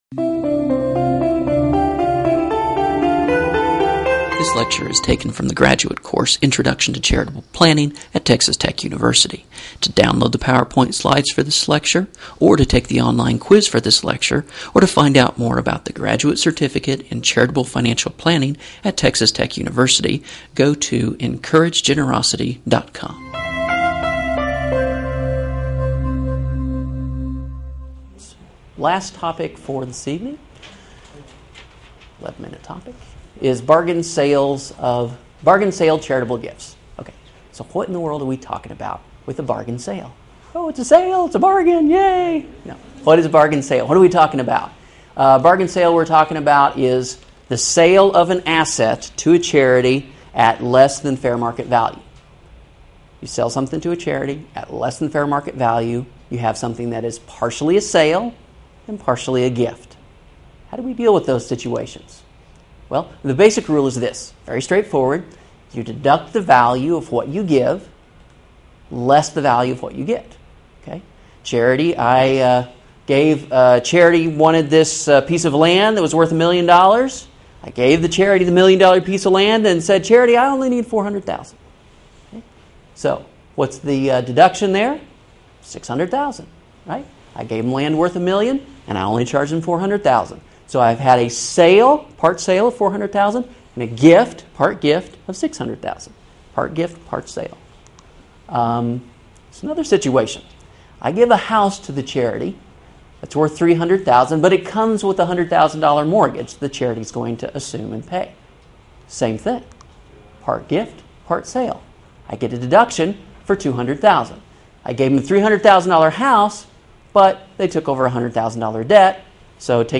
Lectures from Graduate Curriculum in Charitable Financial Planning